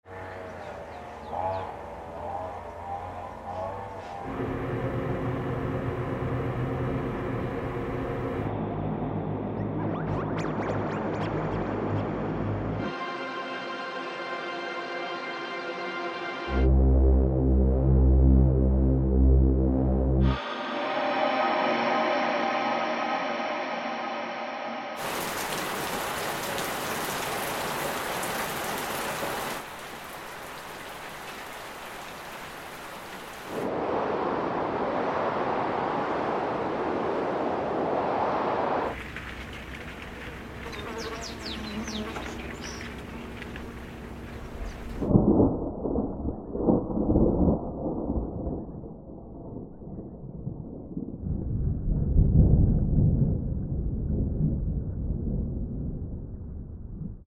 A collection of diverse ambient sounds. Includes rain recordings, isolated thunder in the distance and designed drones.
Preview-Ambiances-Collection.mp3